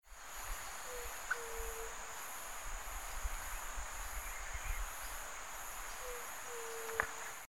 Yerutí Común (Leptotila verreauxi)
Nombre en inglés: White-tipped Dove
Localidad o área protegida: Delta del Paraná
Condición: Silvestre
Certeza: Vocalización Grabada